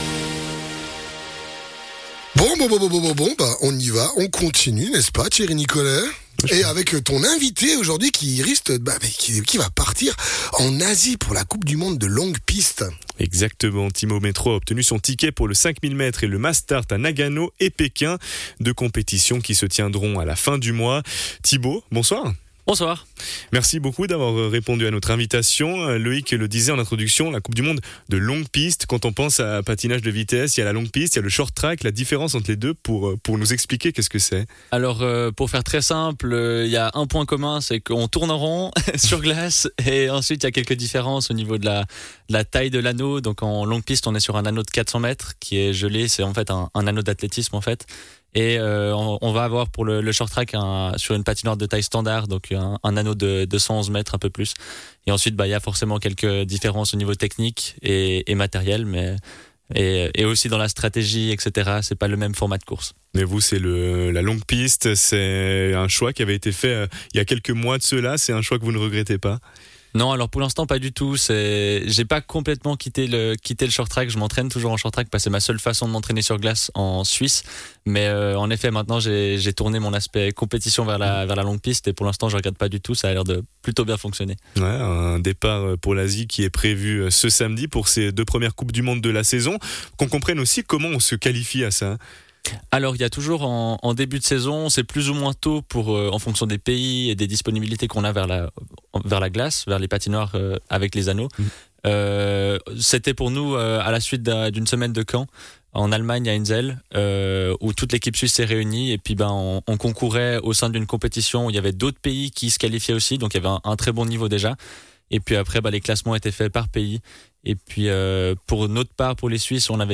patineur